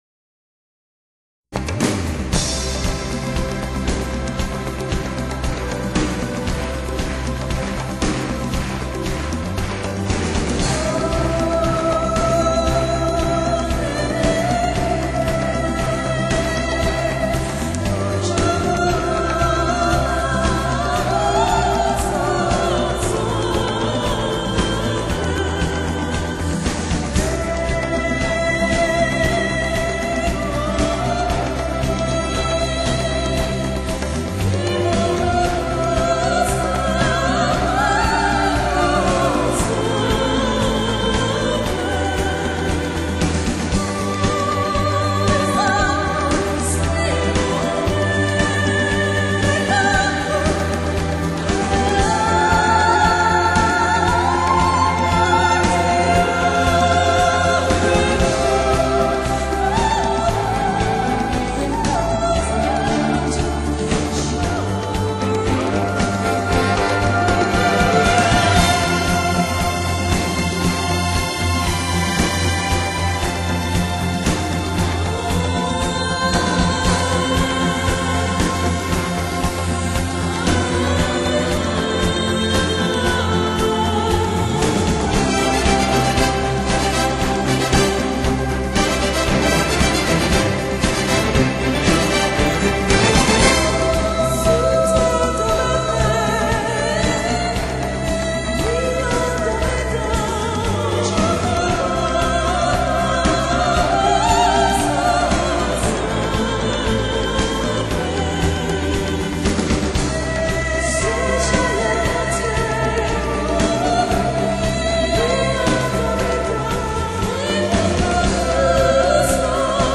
几分古典音乐的风骨和气派；通俗流行中又带有超脱情调；